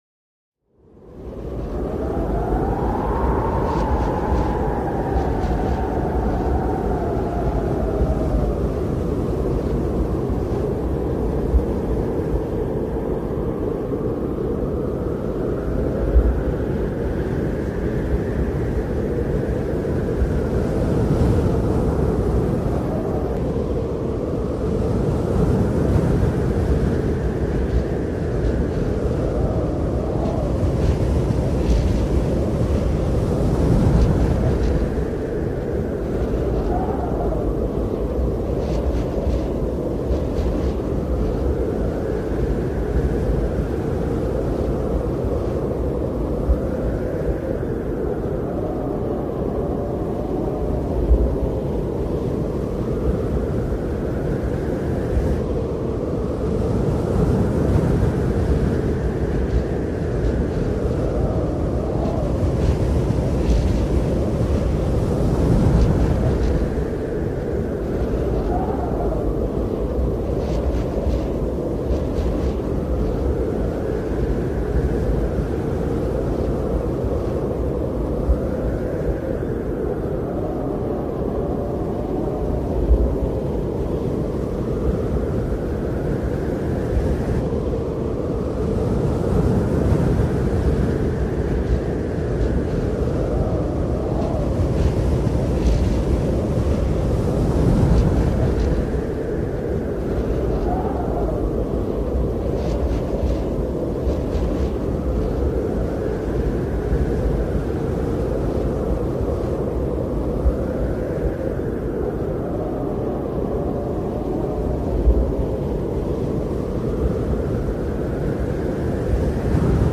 Snow Sound
Tags: game